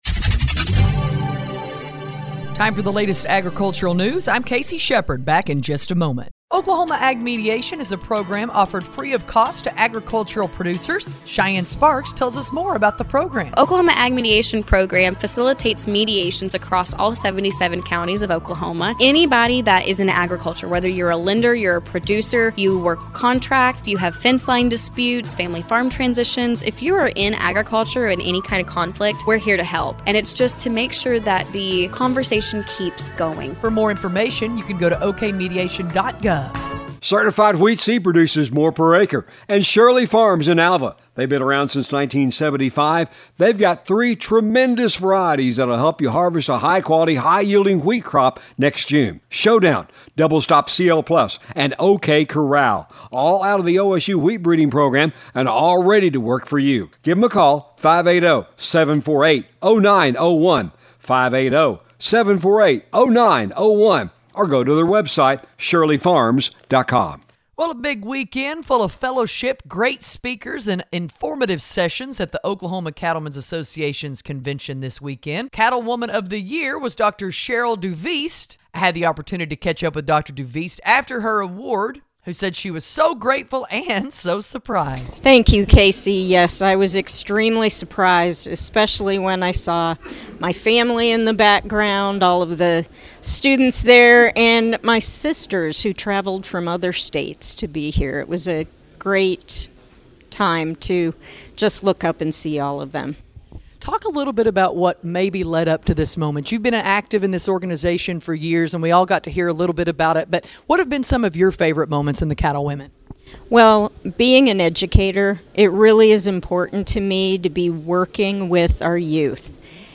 We invite you to listen to us on great radio stations across the region on the Radio Oklahoma Ag Network weekdays-